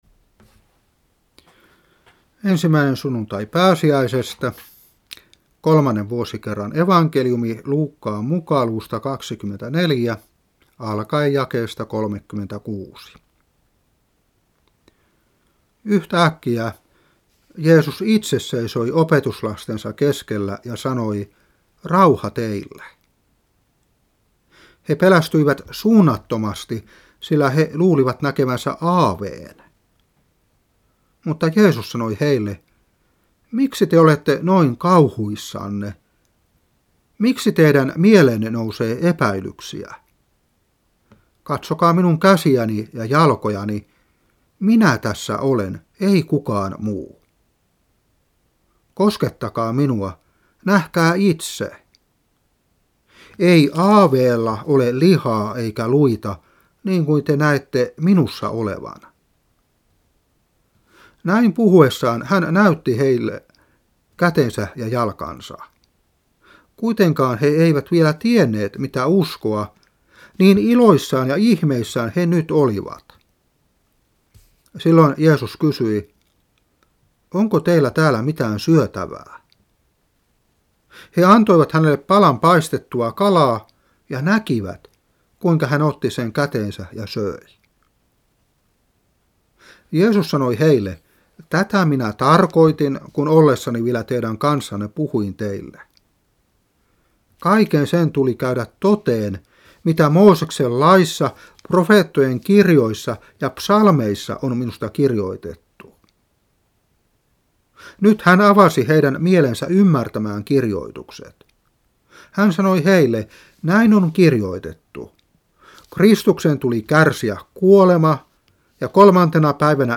Saarna 2015-4.